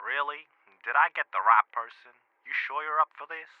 Voice Lines
really did i get the right person.wav